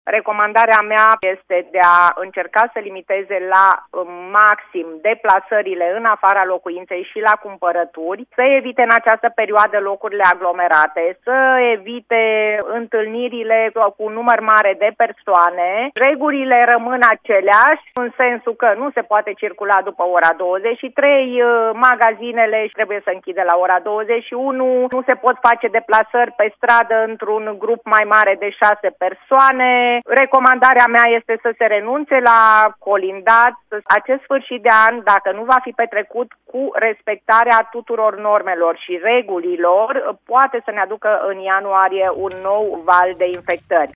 Prefectul de Timiș, Liliana Oneț, a subliniat că prudența și evitarea aglomerației sunt esențiale pentru prevenirea îmbolnăvirii cu virusul SARS-CoV-2.